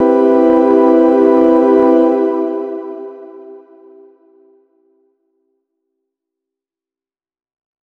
002_LOFI CHORDS MAJ7_2.wav